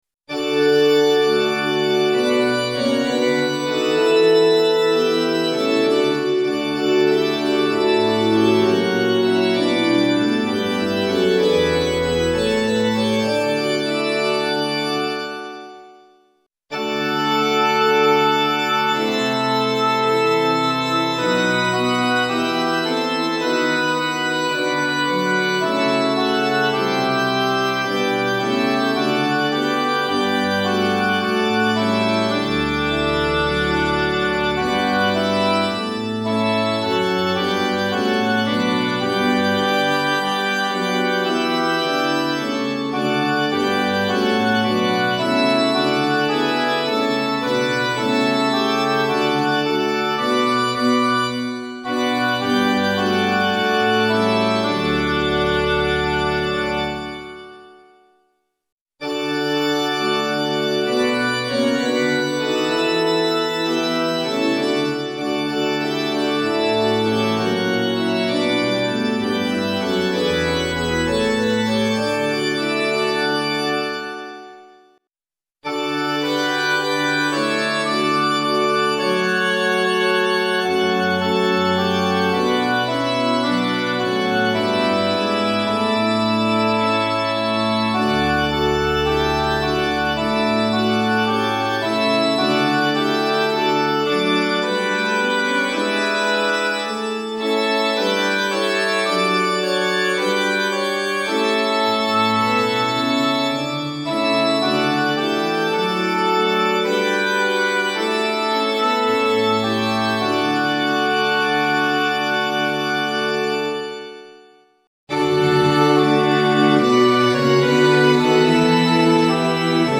混声四部合唱+器楽 Four-part mixed chorus with Instruments
Sample Sound ：参考音源 - 様々な音色の組み合わせによる
DL Rcd+Org Ob+Org Fl+Org Ob+Org Ob+Org+Str